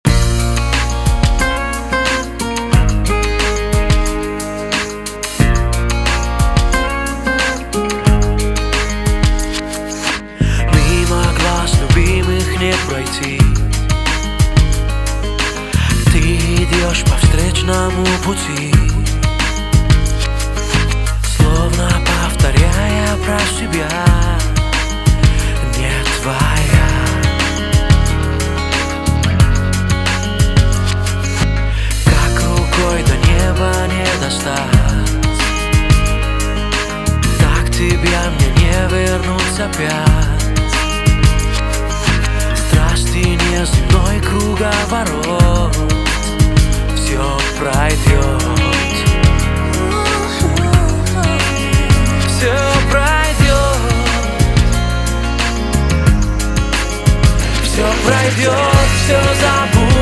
Pop
Долгожданный концертный альбом